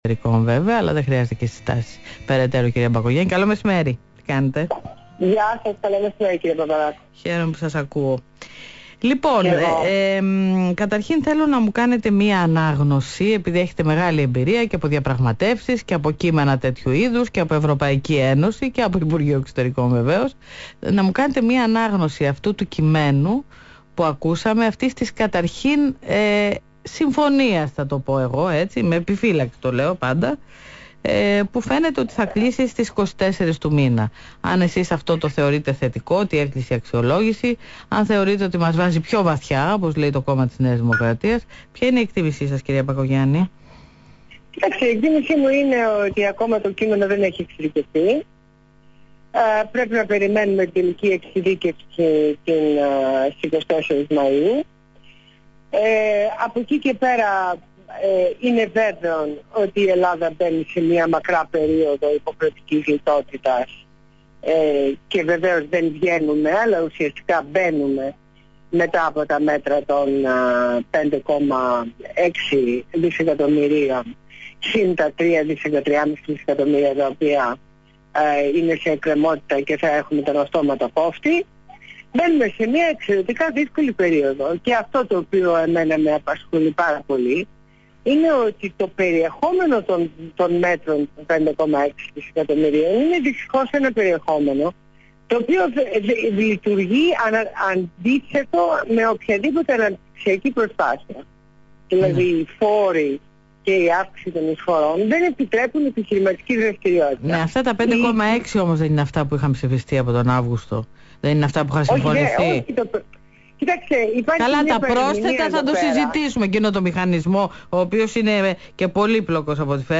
Συνέντευξη στο ραδιόφωνο του ALPHA 98,9